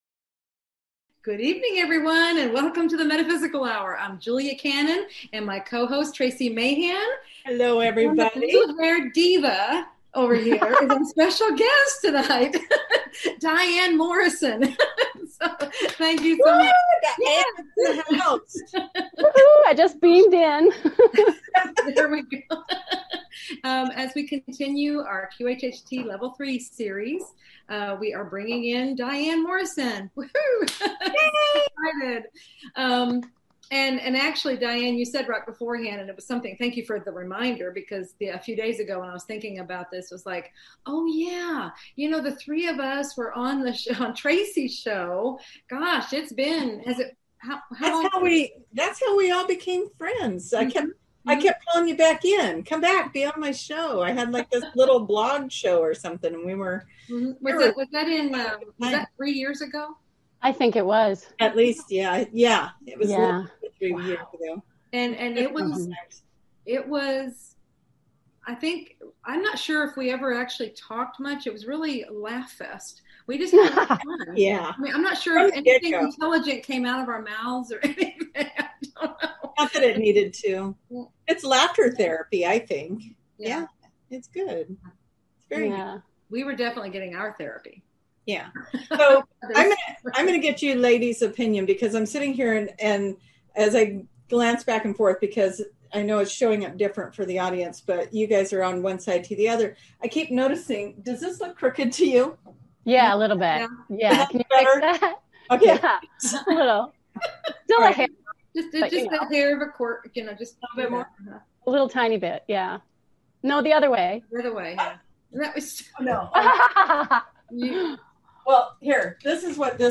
The Metaphysical Hour Talk Show